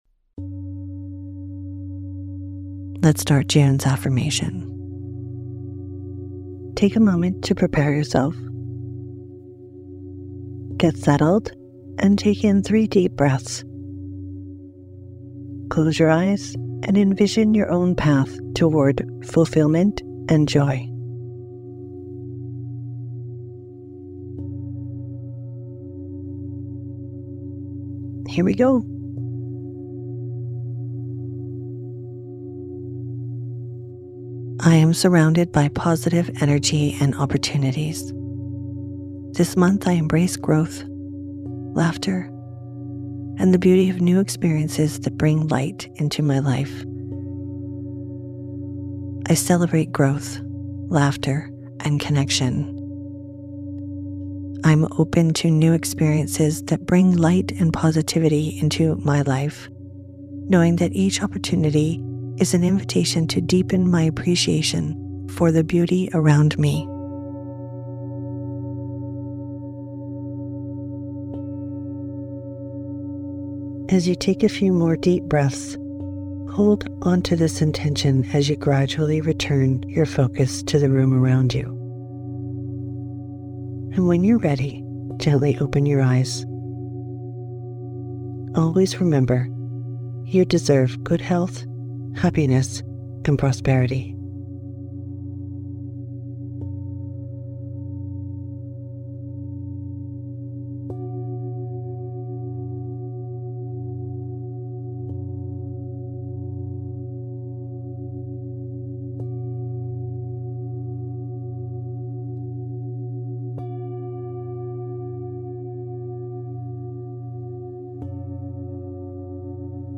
Background Music: “Buddha Bells” by Xumantra